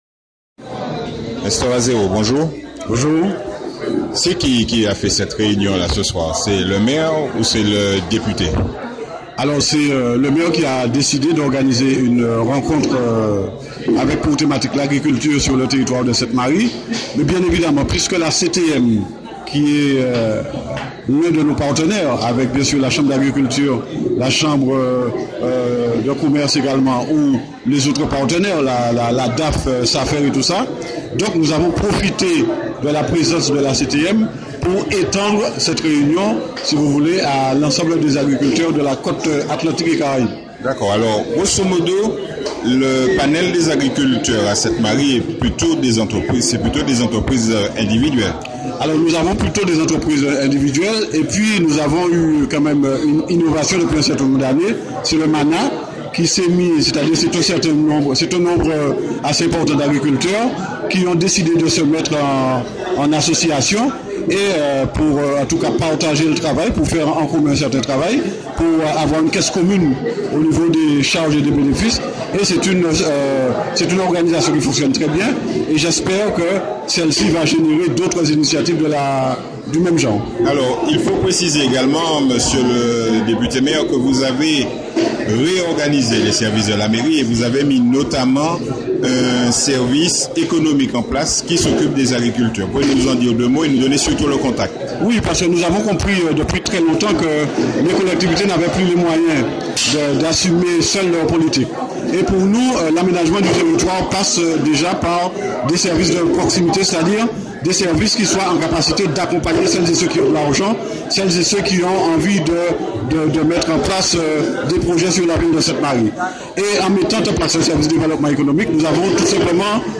Aujourd'hui, nous illustrons notre dossier sur l’état de l’agriculture Martiniquaise sur MAKACLA avec l'interview de Bruno Nestor AZEROT.